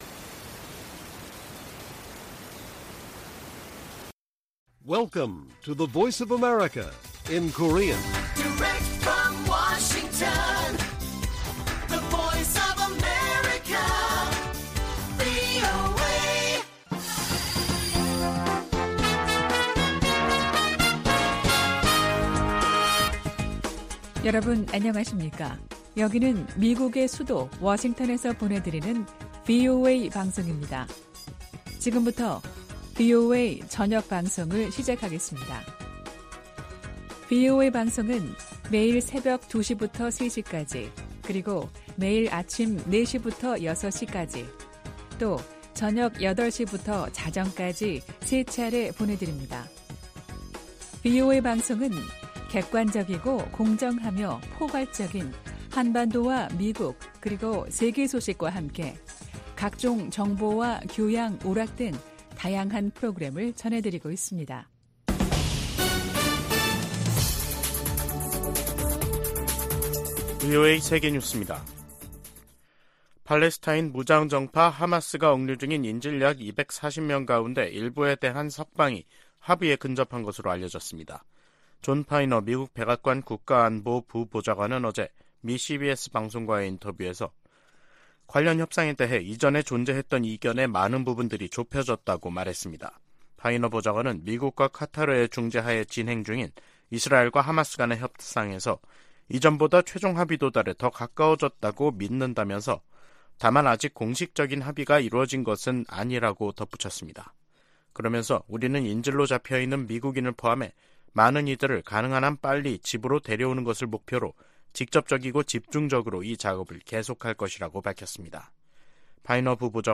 VOA 한국어 간판 뉴스 프로그램 '뉴스 투데이', 2023년 11월 20일 1부 방송입니다. 아시아태평양경제협력체(APEC) 21개 회원국들이 다자무역의 중요성을 강조하는 ‘2023 골든게이트 선언’을 채택했습니다. 한국 합동참모본부는 북한에 3차 군사정찰위성 발사 준비를 중단하라는 경고성명을 냈습니다. 미중 정상회담으로 두 나라간 긴장이 다소 완화된 것은 한반도 정세 안정에도 긍정적이라고 전문가들이 진단했습니다.